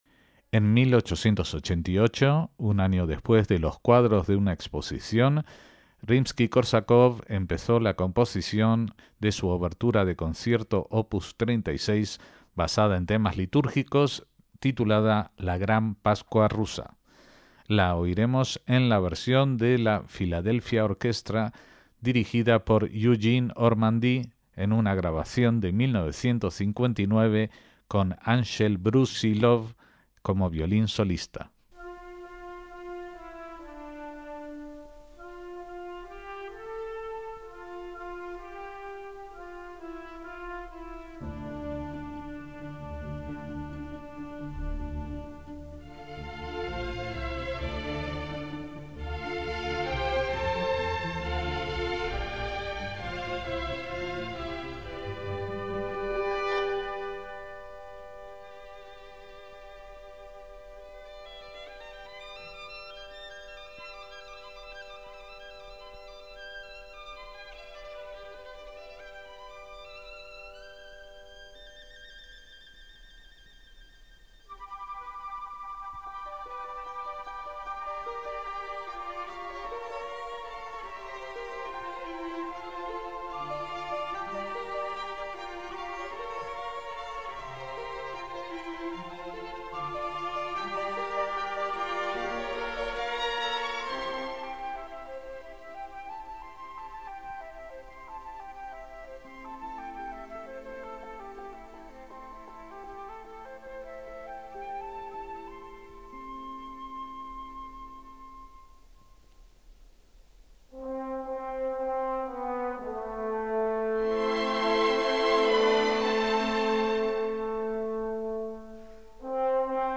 MÚSICA CLÁSICA
obertura de concierto